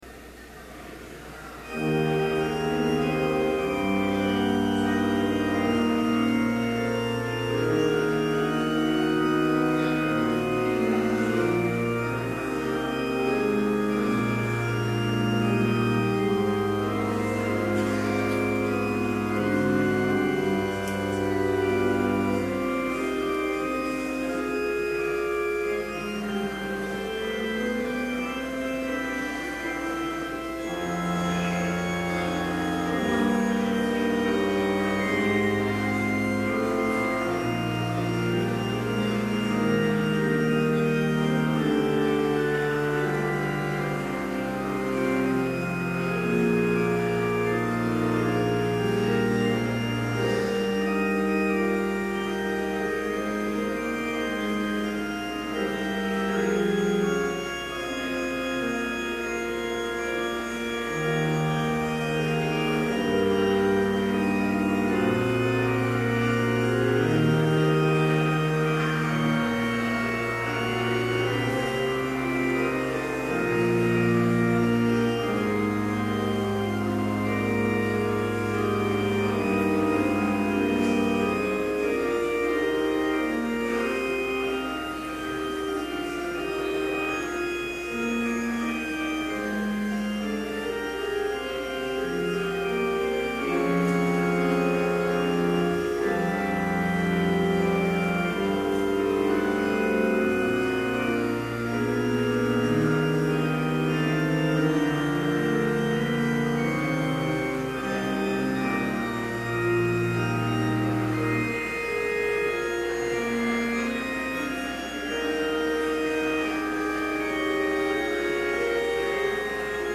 Complete service audio for Chapel - September 1, 2011